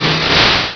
pokeemerald / sound / direct_sound_samples / cries / cascoon.aif
cascoon.aif